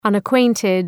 Προφορά
{,ʌnək’weıntıd}